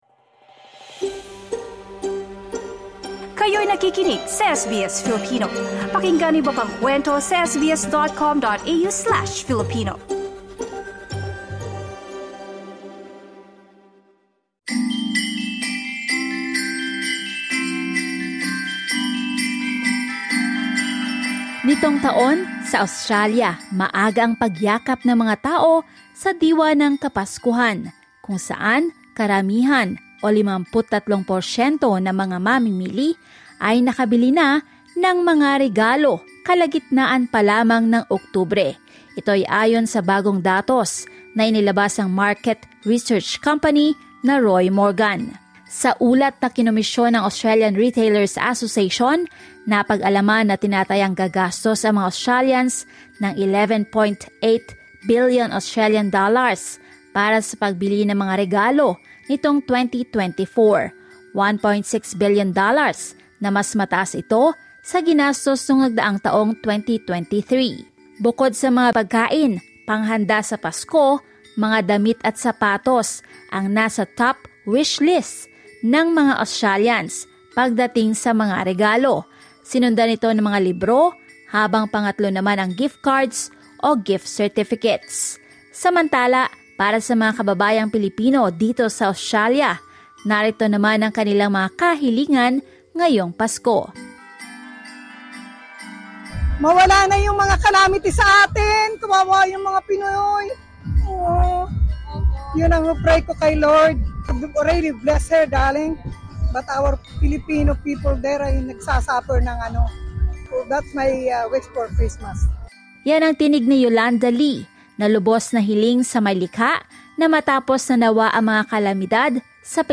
A number of Filipinos in Australia share what they wish for this Christmas Credit: SBS Filipino
CHRISTMAS WISHES VOXPOP FILIPINO 2024